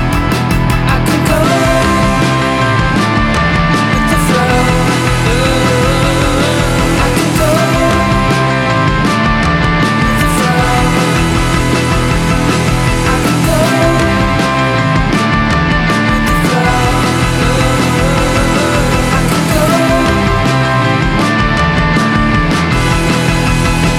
no Backing Vocals Rock 3:04 Buy £1.50